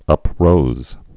(ŭp-rōz)